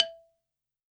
52-prc05-bala-e3.wav